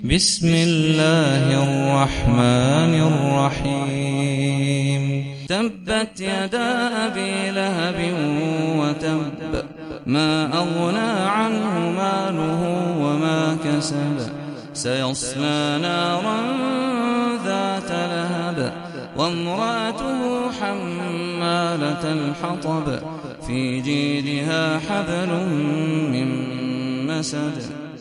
سورة المسد - صلاة التراويح 1446 هـ (برواية حفص عن عاصم)